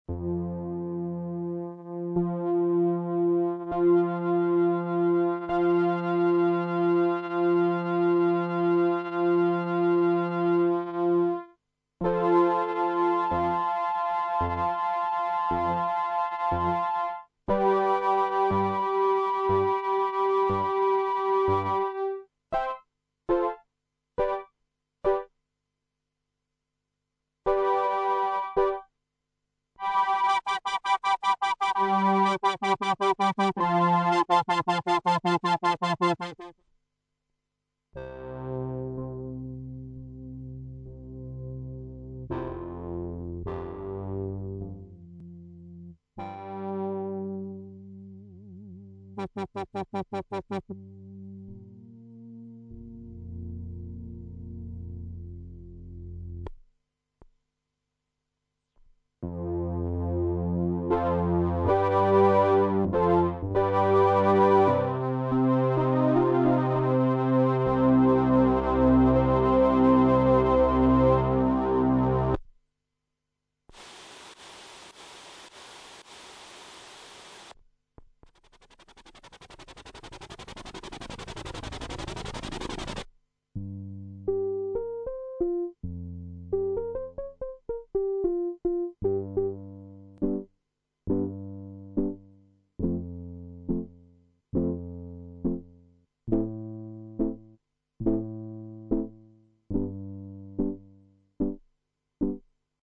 Sound demo
Demo Excerpt (Vintage Synths)
korg_poly800.mp3